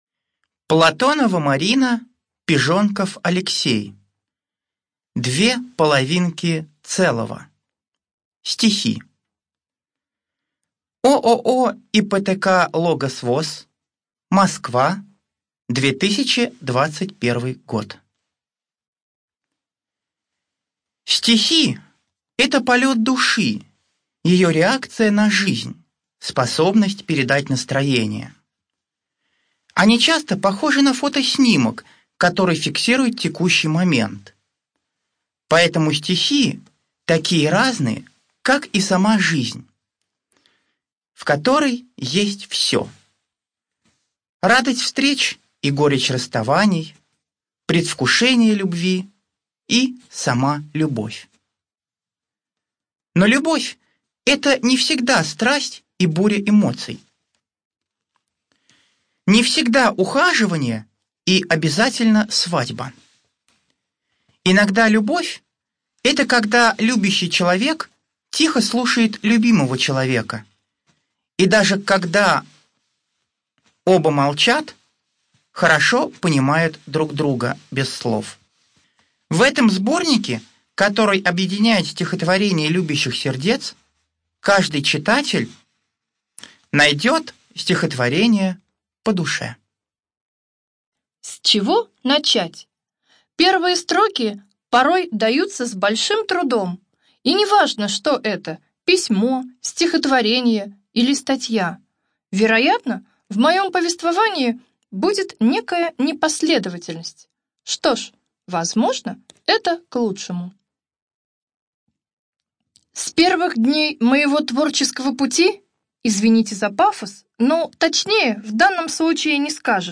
ЖанрПоэзия
Студия звукозаписиТатарская республиканская специальная библиотека для слепых и слабовидящих